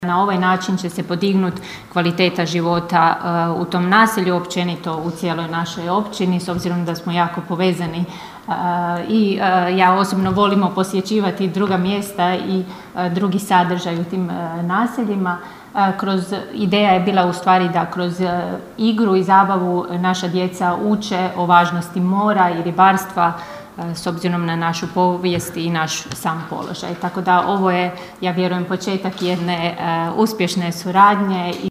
ton – Ana Vuksan), rekla je općinska načelnica Ana Vuksan.